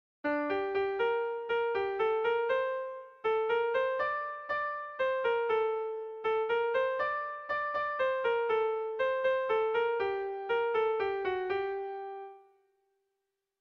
ABBD